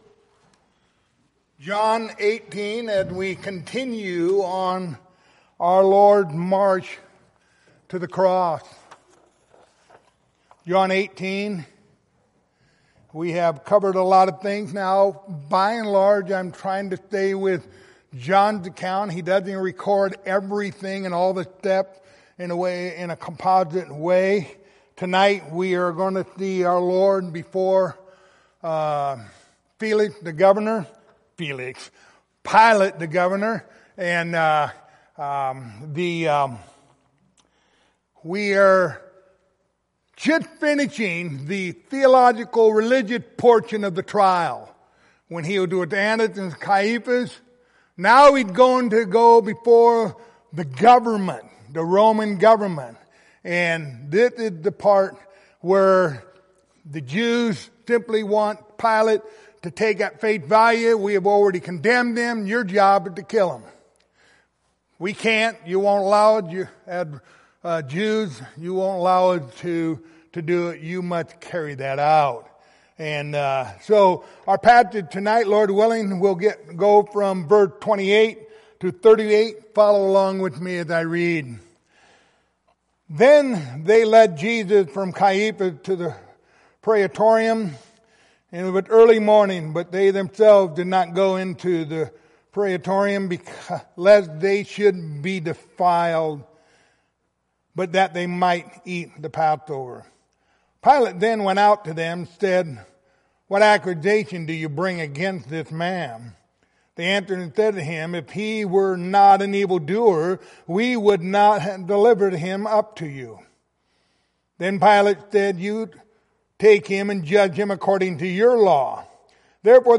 Passage: John 18:28-38 Service Type: Wednesday Evening